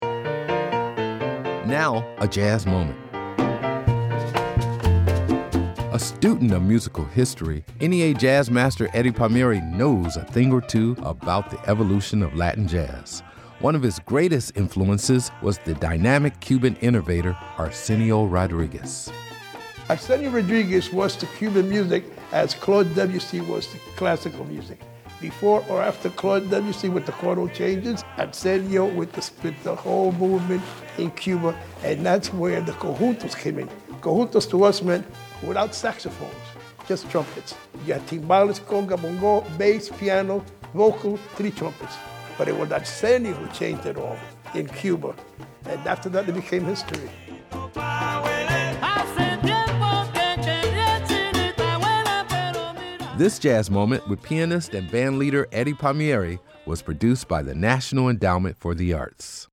Pa'Huele up under
Excerpt of "Pa'Huele" composed by Arsenio Rodriguez and performed by Eddie Palmieri on the album, El Virtuoso: A Man and His Music, used courtesy of Fania Records and by permission of Peer Music (BMI)